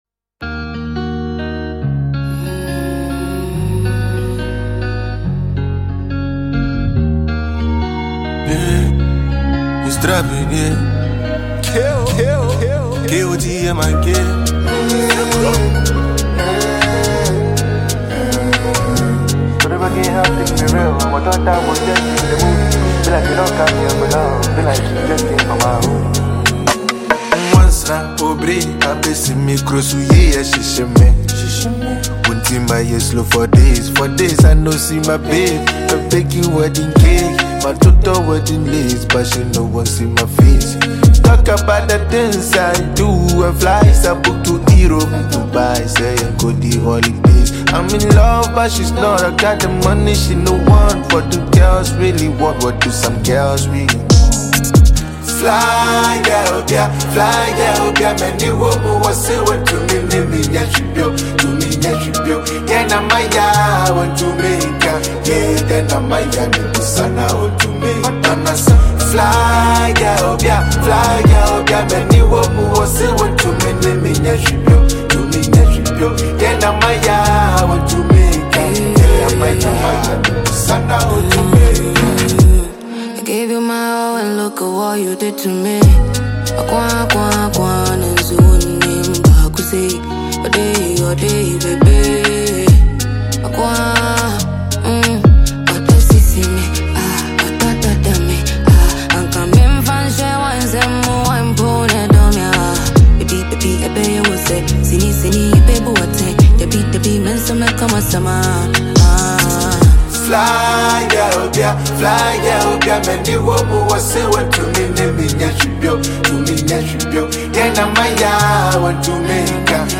Ghana MusicMusic
Ghanaian traditional trapper